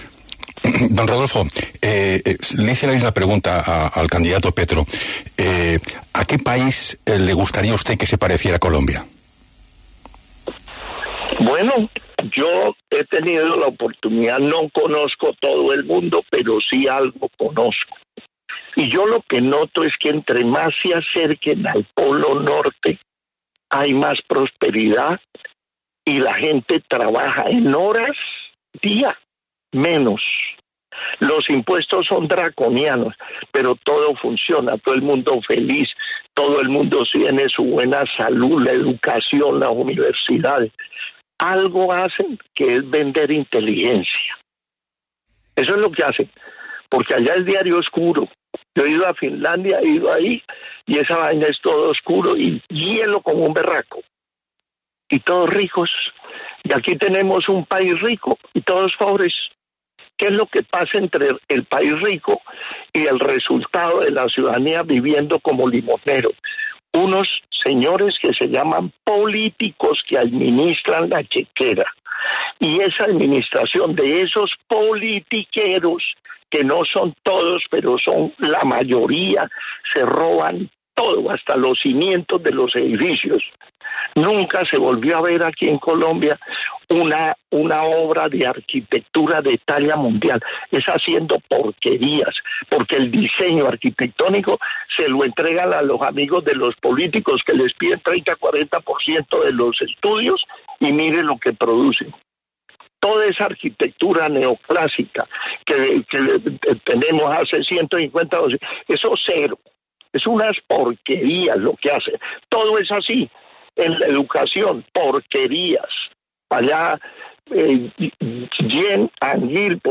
En entrevista con Julio Sánchez Cristo para La W el candidato presidencial Rodolfo Hernández respondió a la pregunta sobre a qué país le gustaría que se pareciera Colombia.